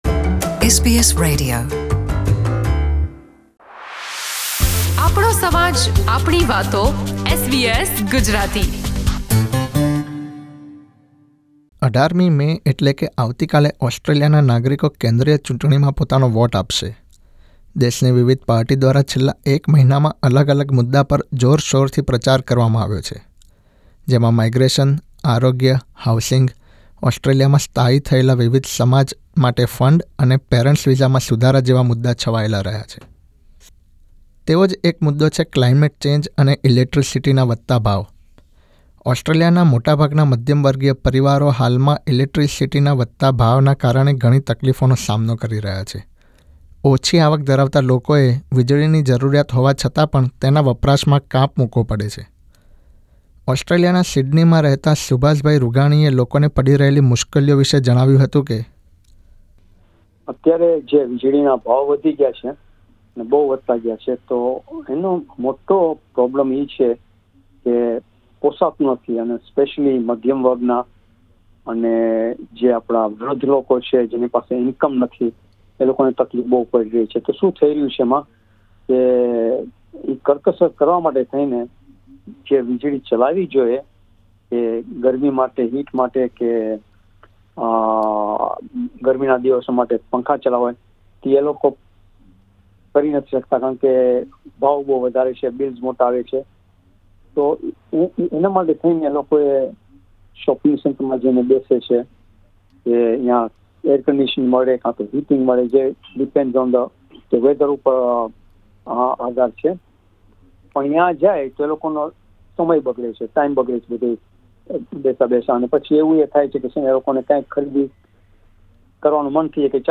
ઓસ્ટ્રેલિયામાં યોજાઇ રહેલી કેન્દ્રીય ચૂંટણીમાં ક્લાઇમેટ ચેન્જ પોલિસી મહત્વનો ભાગ ભજવે તેવી શક્યતા છે. બીજી તરફ, રાજકીય પક્ષોએ પણ ચૂંટણી જીતીને સત્તામાં આવ્યા બાદ ક્લાઇમેટ અંગેના પોતાના પ્લાન અને પોલિસી અમલમાં મૂકવાની જાહેરાત કરી છે ત્યારે, SBS Gujarati એ વિજળીના વધતા ભાવનો સામનો કરી રહેલા વરિષ્ઠ નાગરિક સાથે વર્તમાન પરિસ્થિતિ તથા આગામી સરકાર પાસે ક્લાઇમેટ ચેન્જની અપેક્ષાઓ વિશે વાત કરી હતી.